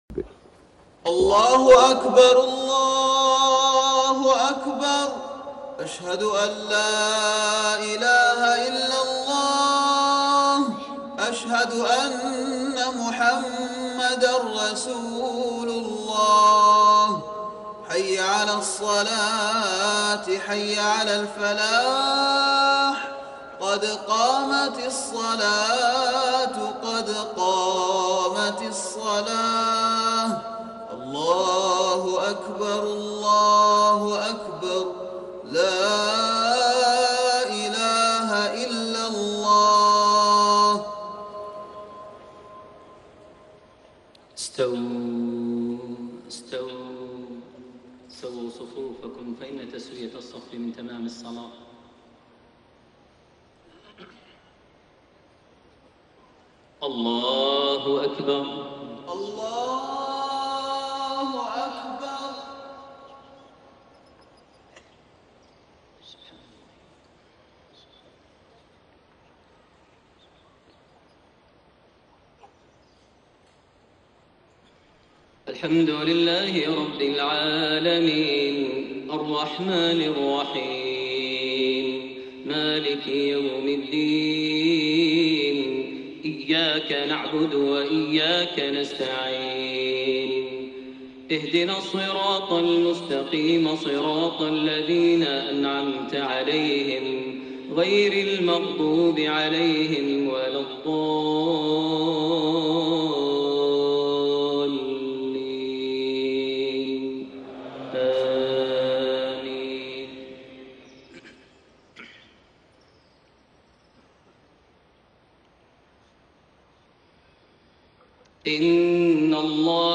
صلاة الفجر 19 رجب 1432هـ | خواتيم سورة الأحزاب 56-73 > 1432 هـ > الفروض - تلاوات ماهر المعيقلي